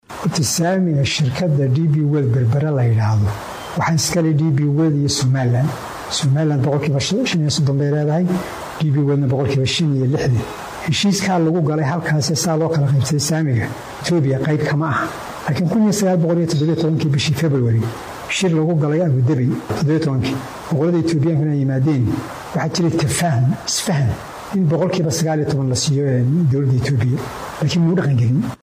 Wasiirka wasaaradda maaliyadda ee jamhuuriyadda iskeed madax bannaanida ugu dhawaaqday ee Soomaliland Sacad Cali Shire oo wareysi siiyay mid ka mid ah warbaahinnada maxalliga ah ee magaalada Hargeysa ka howlgasha ayaa ka hadlay heshiiskii 19 boqolkiiba saamiga dekedda Berbera ay ku yeelanaysay dowladda Itoobiya. Waxaa uu sheegay in gabi ahaanba heshiiska saamigaas uu xilli hore burburay islamarkaana dowladda Itoobiya waxba ku lahayn dakhliga ka soo xaroonaya Dekedda Berbera.